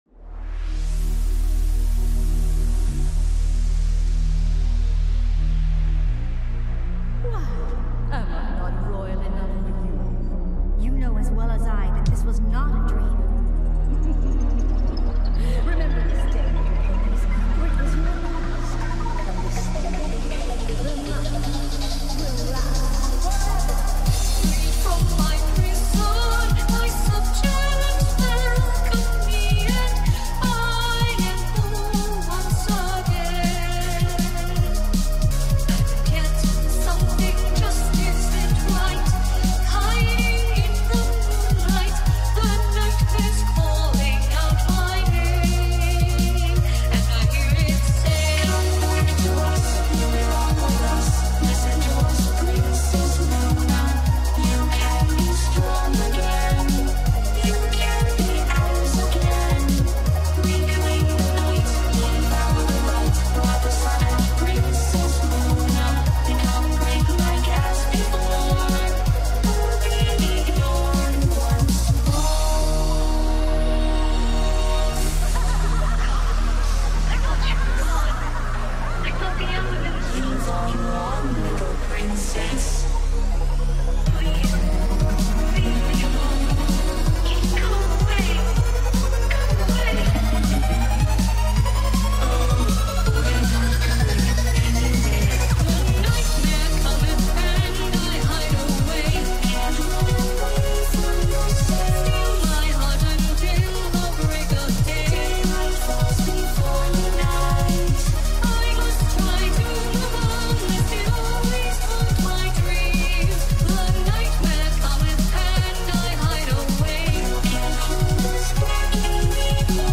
liquid drum and bass song